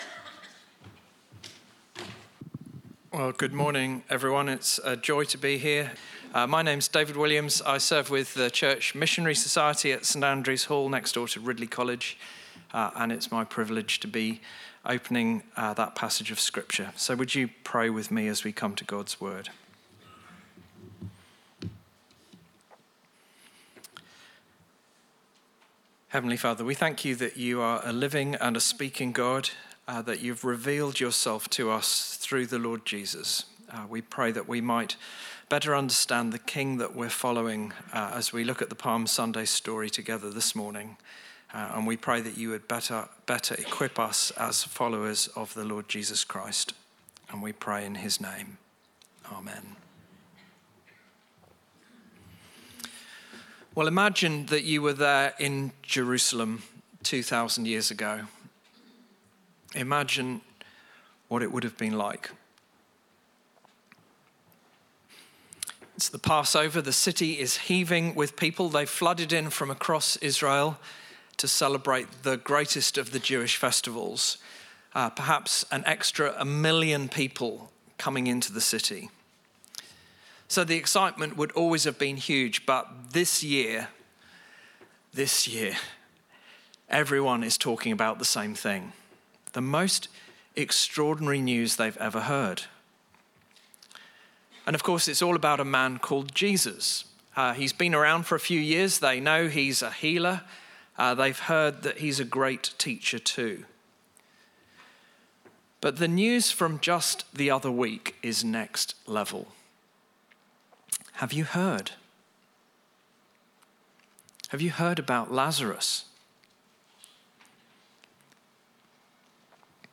Sermons | St Alfred's Anglican Church
Bible Passage